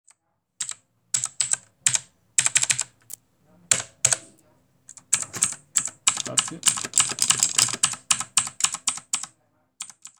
In effetti, pesa quasi un Kg ed esso è dovuto alla pannello in metallo, che, alla pressione di un tasto, favorisce un suono tipico di una tastiera meccanica di fascia alta.
Gli switch utilizzati da questa tastiera sono “OUTEMU Blue Switch“, ovvero una imitazione ben riuscita degli switch Cherry MX Blue.
Qui potete ascoltare il suono emesso durante la scrittura di un testo.